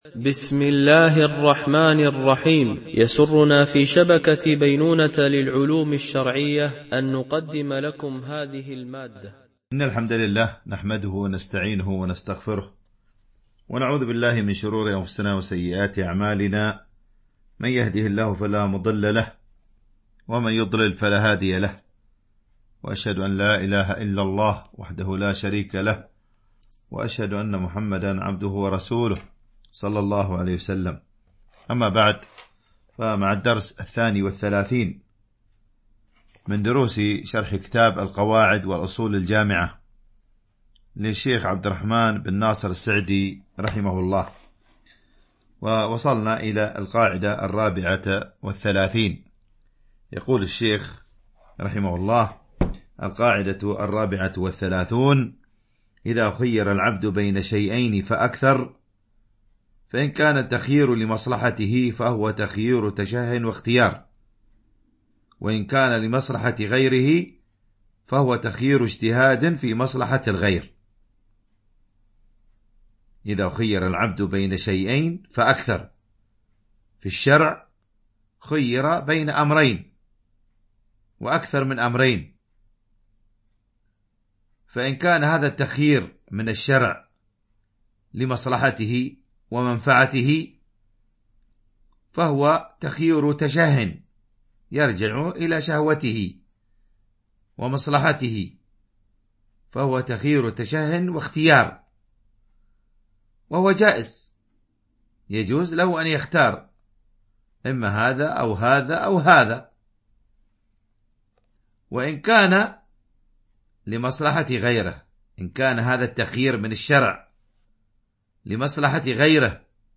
شرح القواعد والأصول الجامعة والفروق والتقاسيم البديعة النافعة - الدرس 32 ( كل متصرف عن الغير عليه أن يتصرف بالمصلحة )